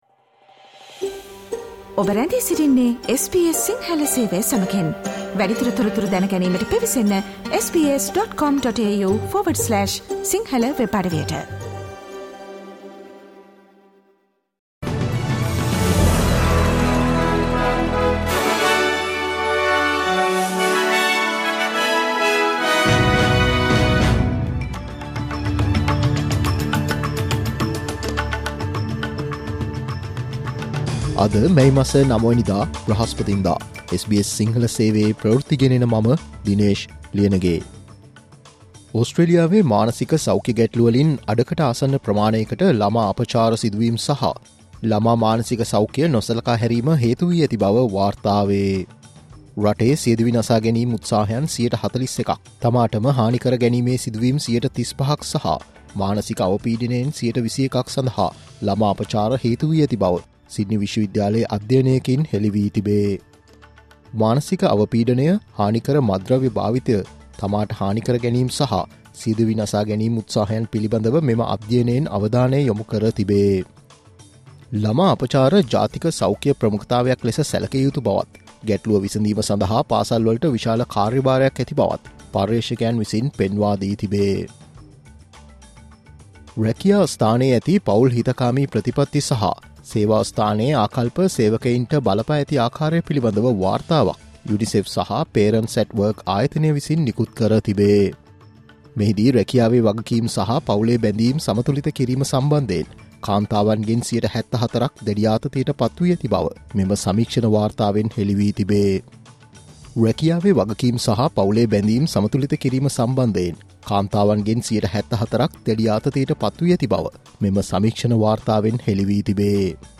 Australian news in Sinhala, foreign and sports news in brief - listen, Sinhala Radio News Flash on Thursday May 09